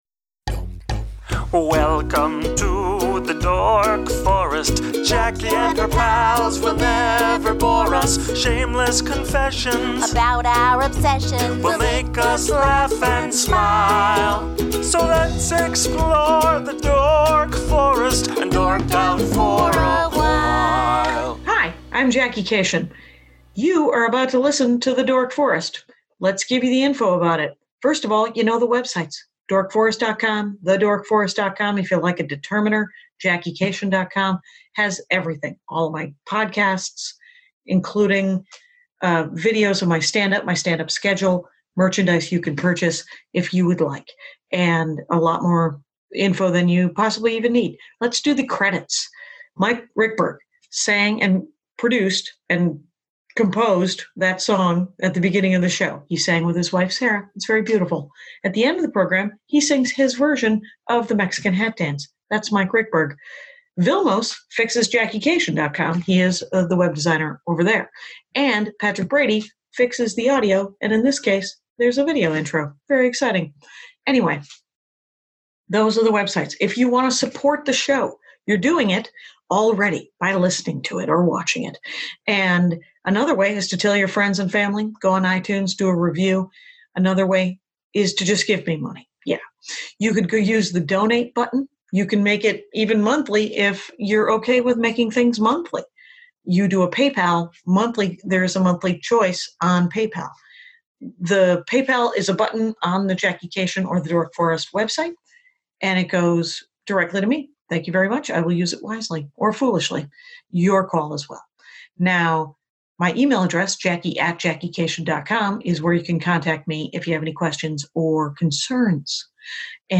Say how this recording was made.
This one was an exciting use of USB sticks and the US Mail. There’s a SLIGHT overlap because Quarantine dorkout.